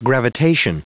Prononciation du mot gravitation en anglais (fichier audio)
Prononciation du mot : gravitation